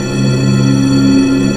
SUN-BELL.wav